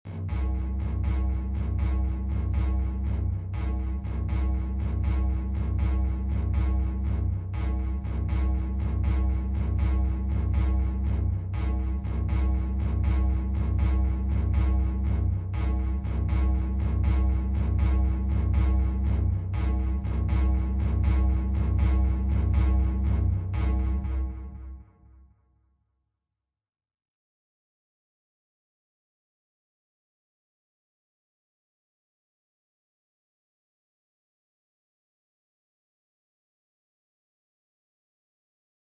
耶稣基督即将到来的悬念贝司样本视频电影影院 " 002 悬念耶稣基督即将到来的贝司B
描述：耶稣基督即将降临巴斯悬念循环（39秒）One Bass乐器，stacatto，悬疑，恐怖
Tag: 恐怖 悬念 恐怖 惊险 恐怖